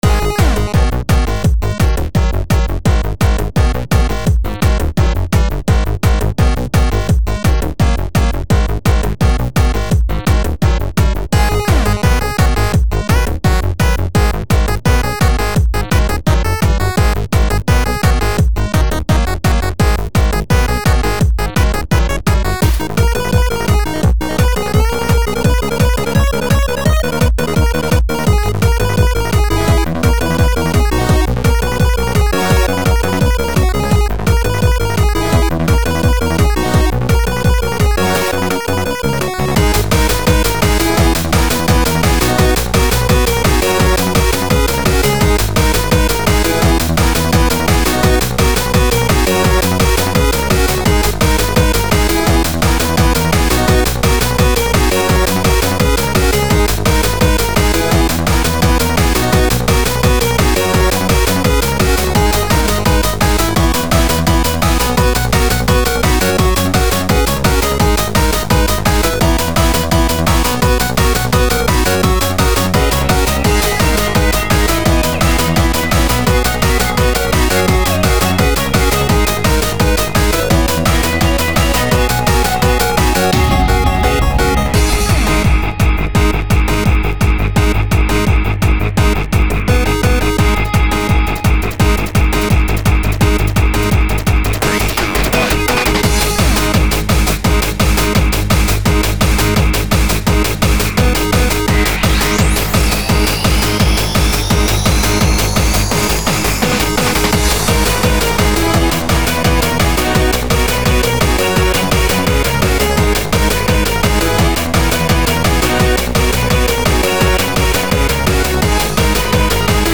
BPM170